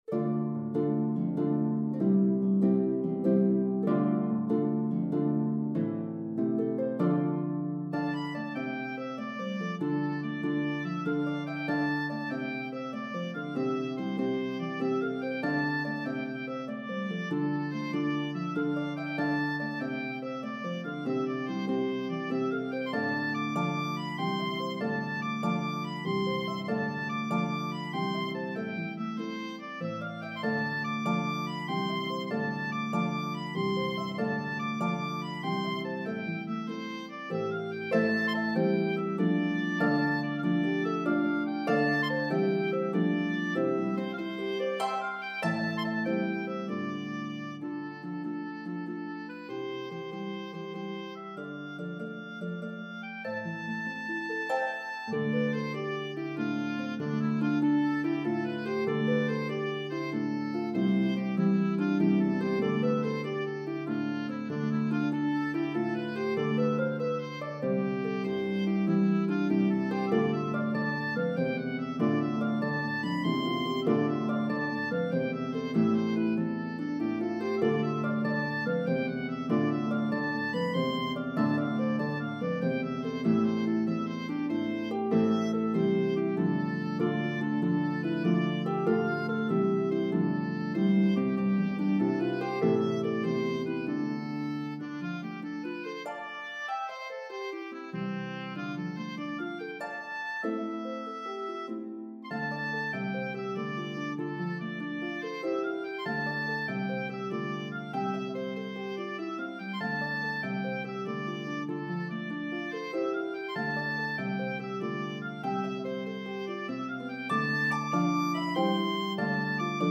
One of the most energetic Step Dances in Slip Jig form.